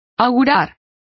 Complete with pronunciation of the translation of predict.